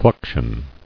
[flux·ion]